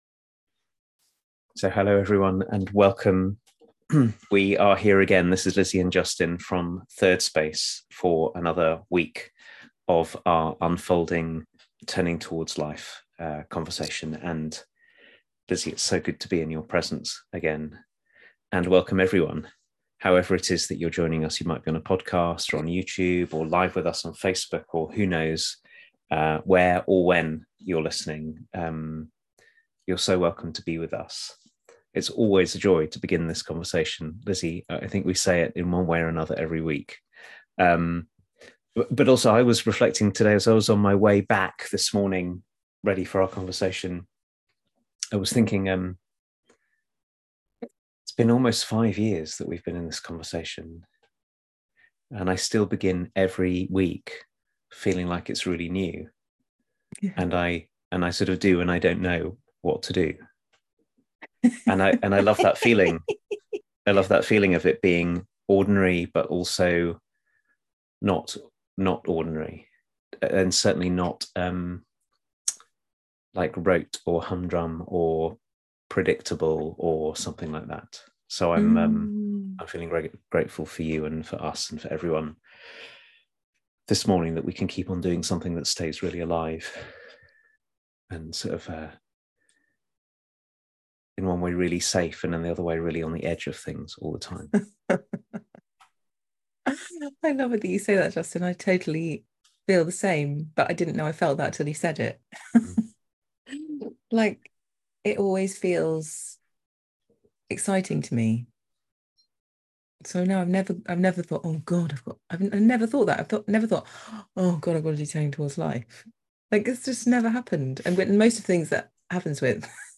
a week-by-week conversation inviting us deeply into our lives